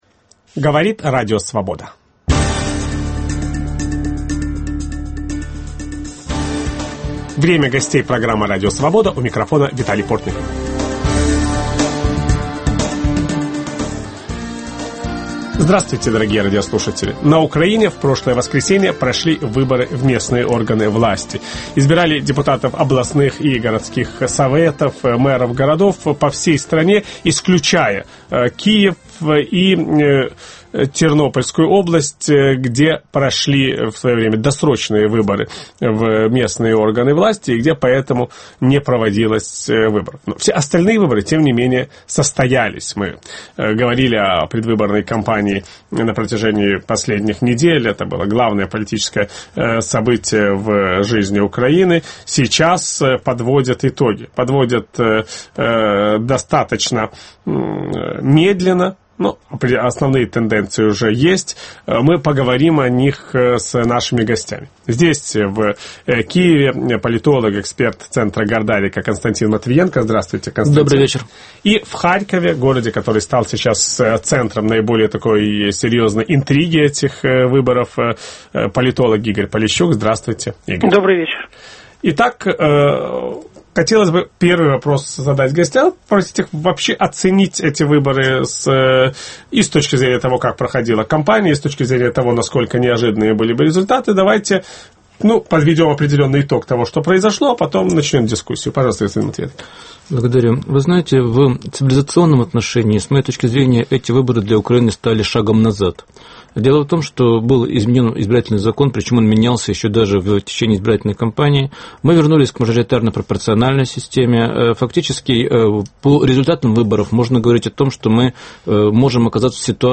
Местные выборы на Украине: успех демократии или успех власти? В программе участвуют политологи